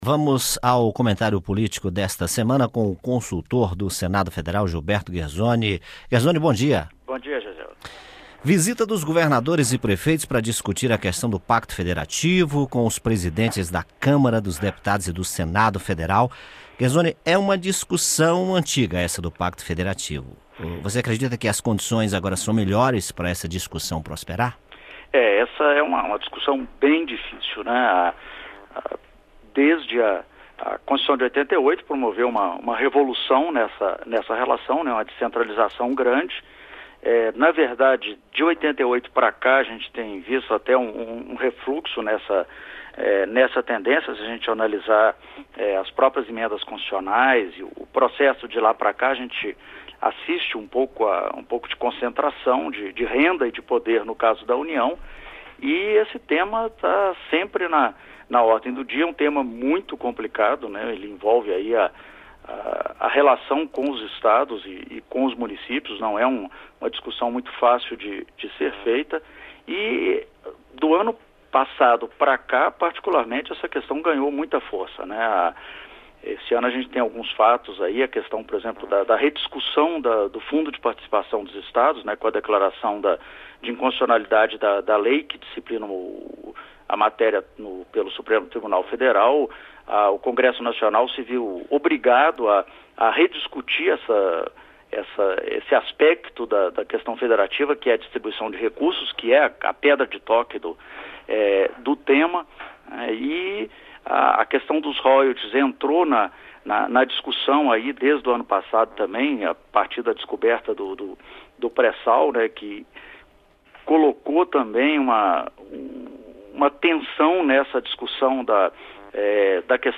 Programa diário com reportagens, entrevistas e prestação de serviços
Comentário político: discussão sobre Pacto Federativo é antiga